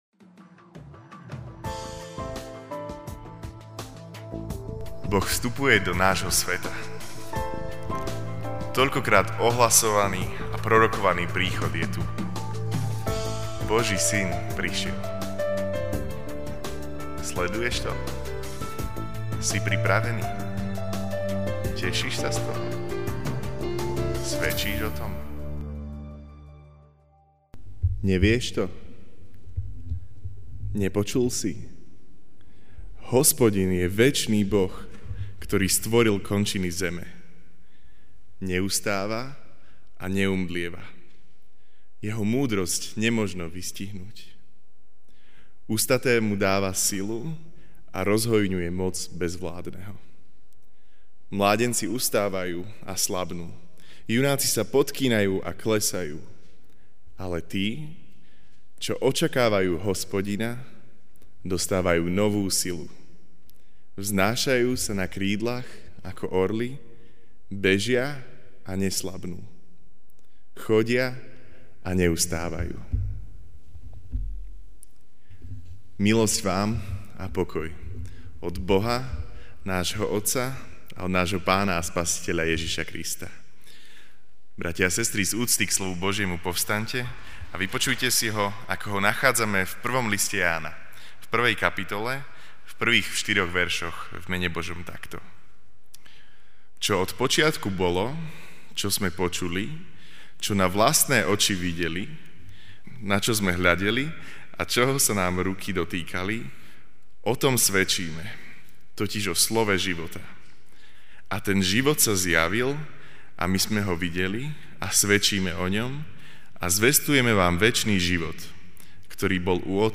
Večerná kázeň: Boh vstupuje do nášho sveta (1J 1,1-4) 'Čo bolo od počiatku, čo sme počuli, čo sme videli na vlastné oči, na čo sme pozerali a čoho sa nám dotýkali ruky, (o tom svedčíme, totiž) o Slove života.